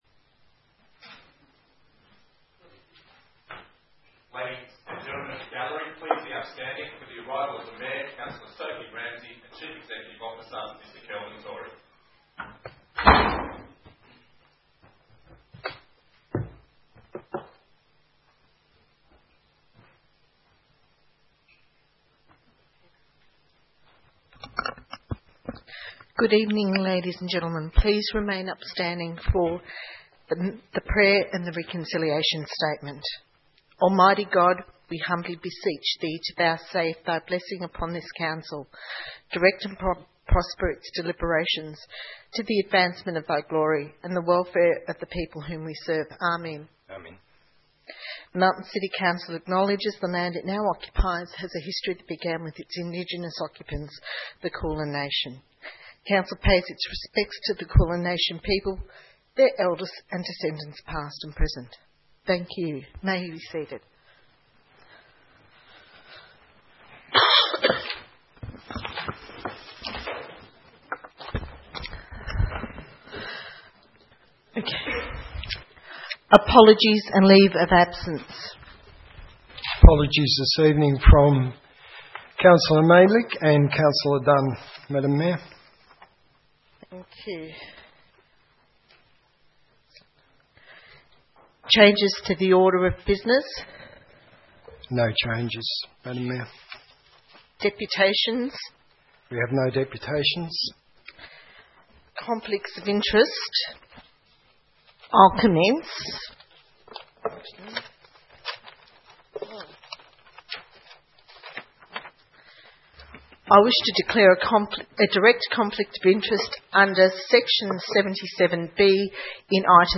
14 July 2015 - Ordinary Council Meeting
audio_of_the_council_meeting.mp3